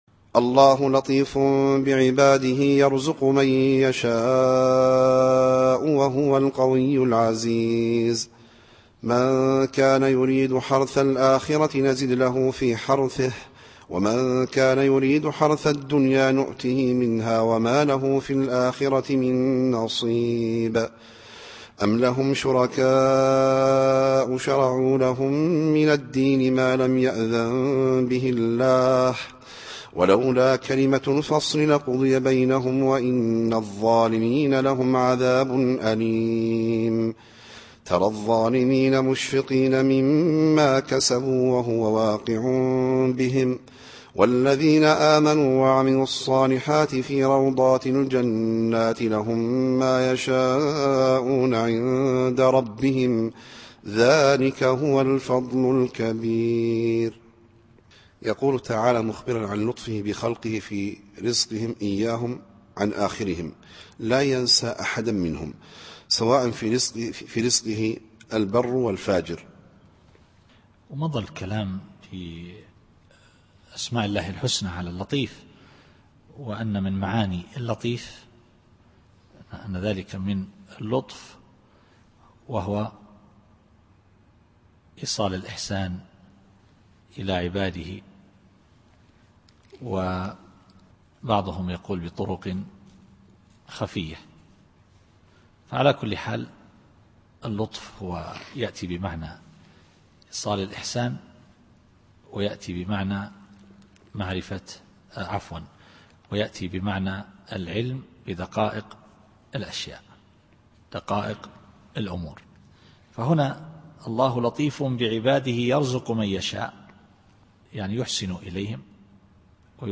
التفسير الصوتي [الشورى / 20]